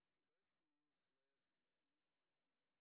sp01_street_snr0.wav